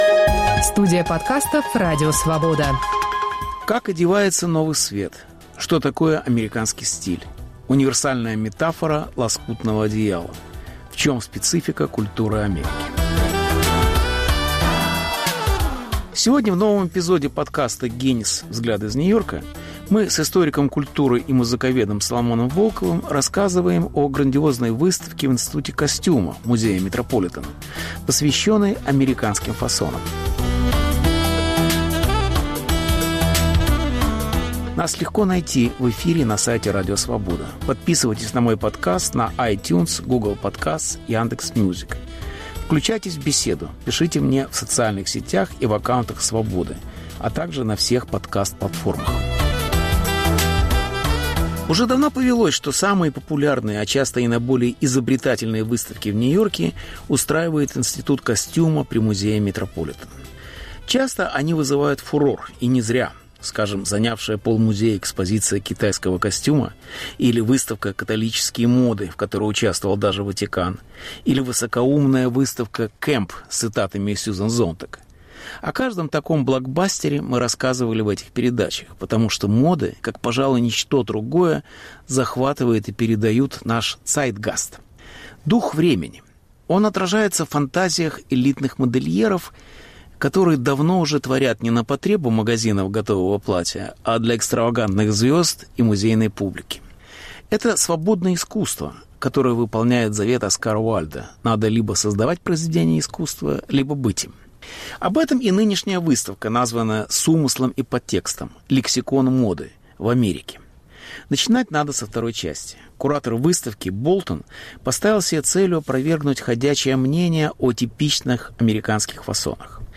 Беседа с Соломоном Волковым об американском стиле в моде - и в культуре. Повтор эфира от 04 октября 2021 года.